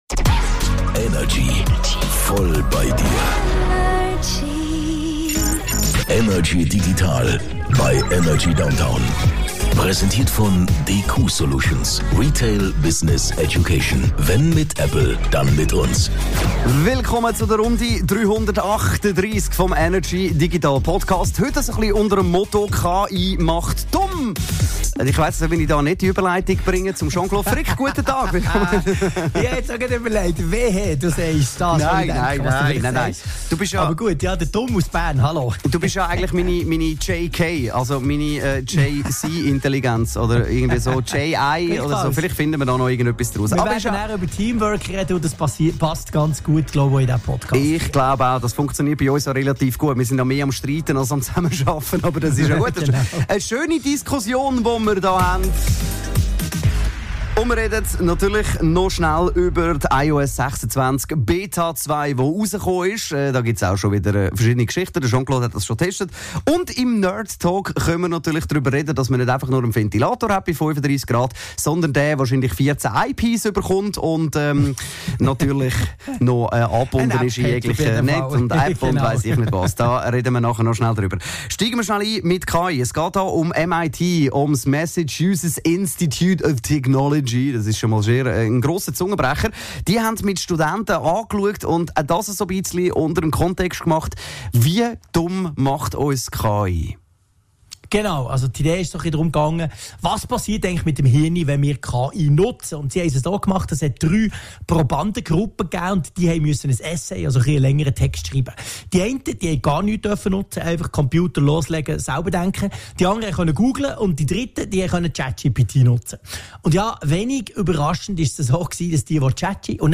aus dem HomeOffice über die digitalen Themen der Woche.